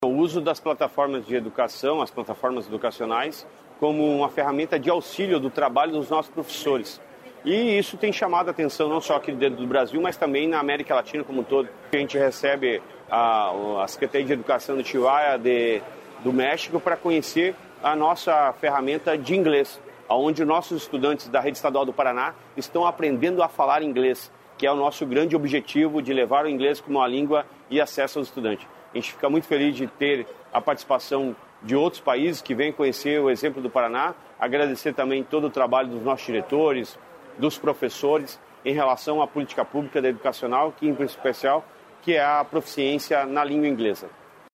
Sonora do secretário da Educação, Roni Miranda, sobre a comitiva de autoridades do México